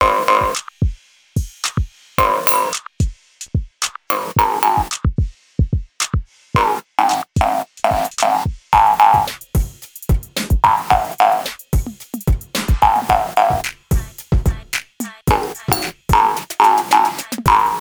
Записано на микрофон iPad'а. Сконвертировано из mp4 в mp3 на каком-то сайте прямо с телефона.